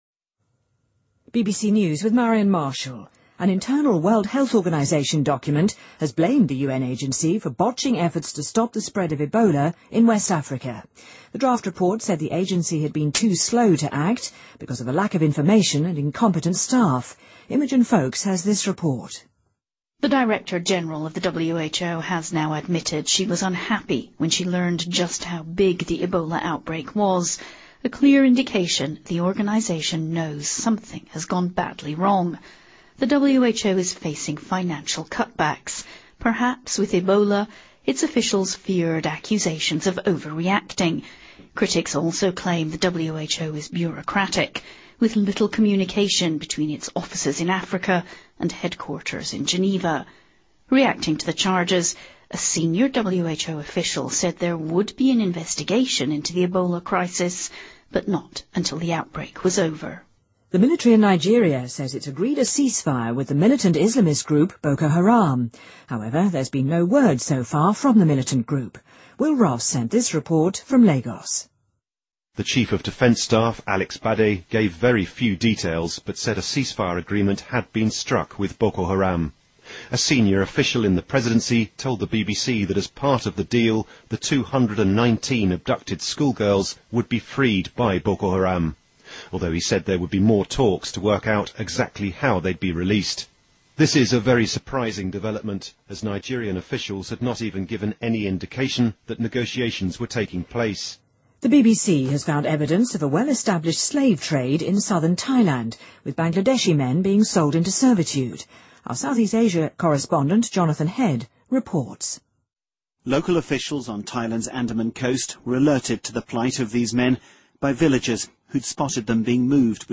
BBC news:世卫组织内部文件指责本机构阻碍了遏制西非埃博拉蔓延的工作|BBC在线收听